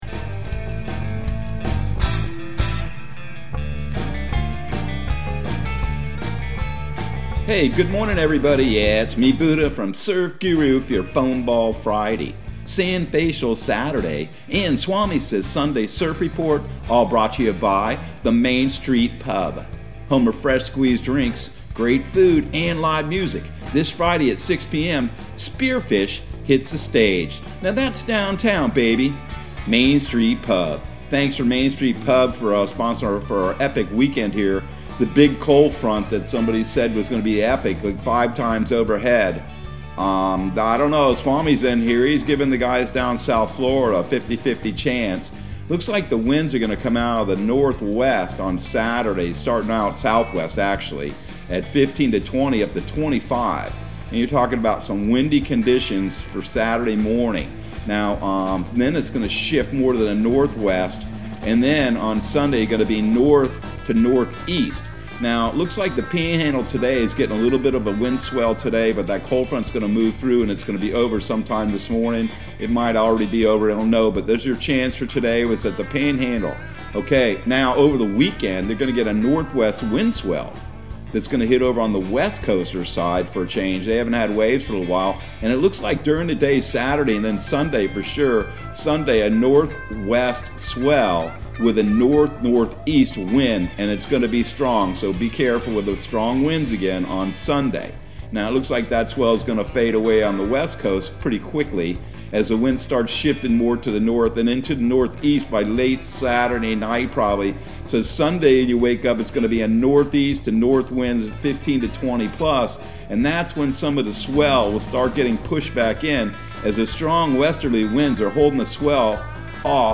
Surf Guru Surf Report and Forecast 03/11/2022 Audio surf report and surf forecast on March 11 for Central Florida and the Southeast.